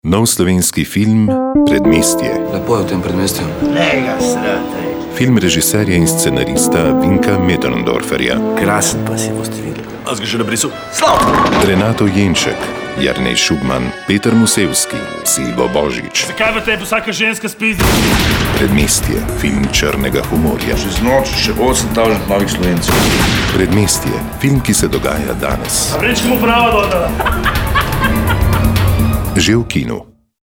Radijski spot (prihaja v kino) Radijski spot (�e v kinu)